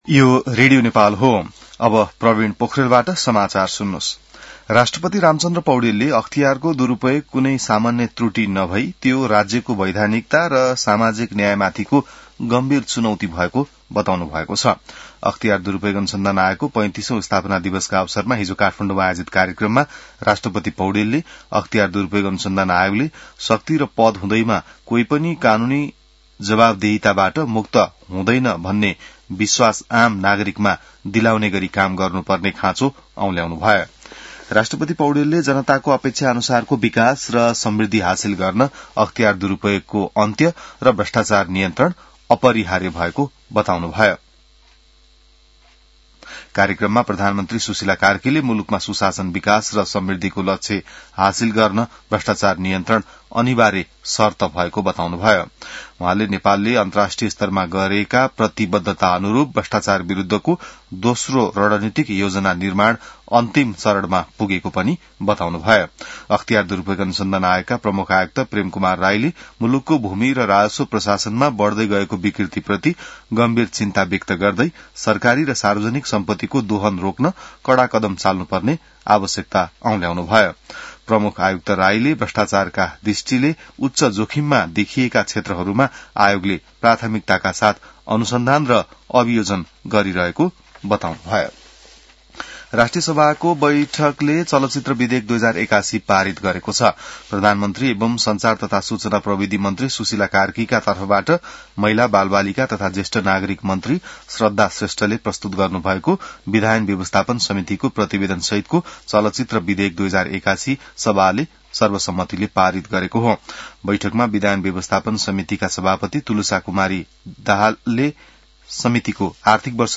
बिहान ६ बजेको नेपाली समाचार : २९ माघ , २०८२